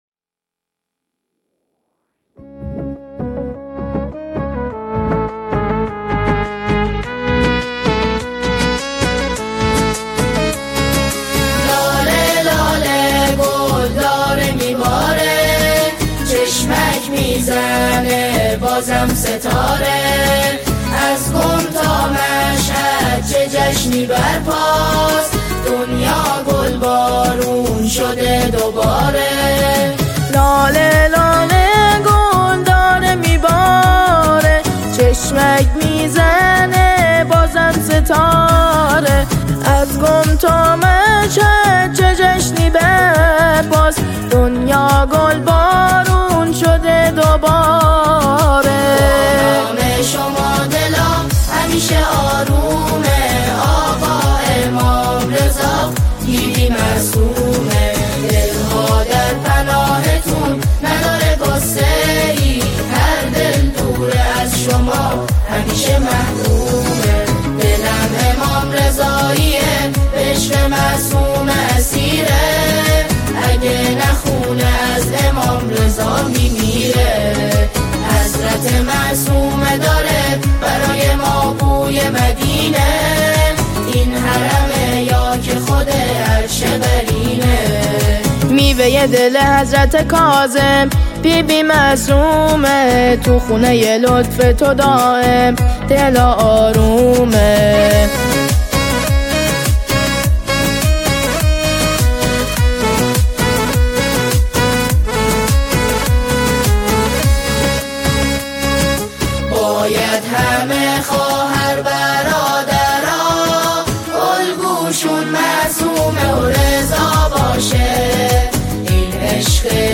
نماهنگ زیبا و دلنشین